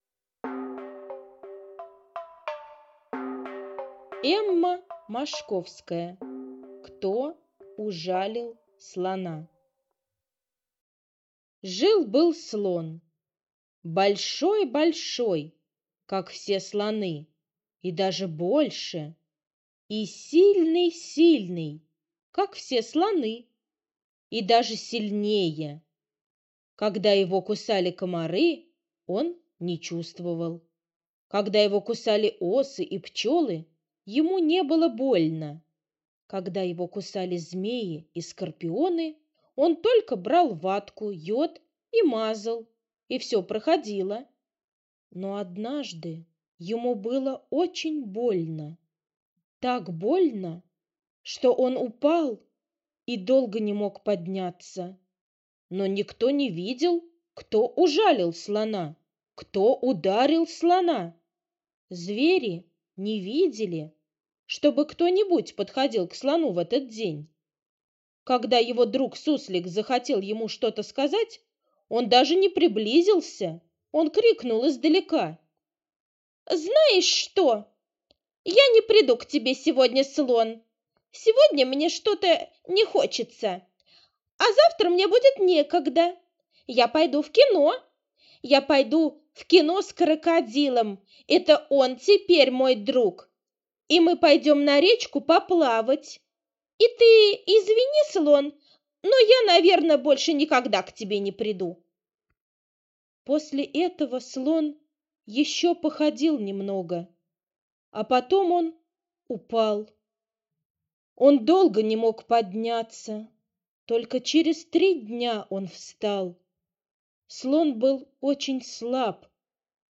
Кто ужалил слона - аудиосказка Мошковской Э.Э. Сказка про дружбу.